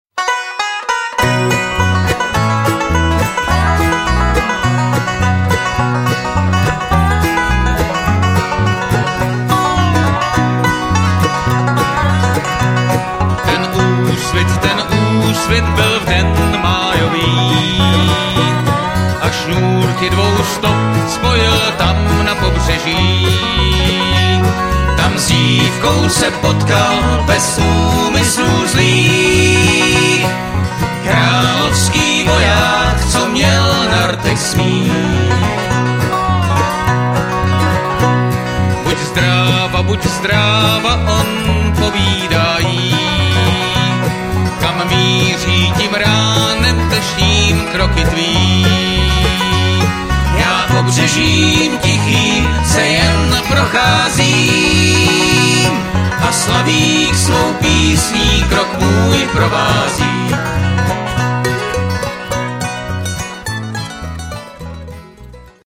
lead
tenor
baritone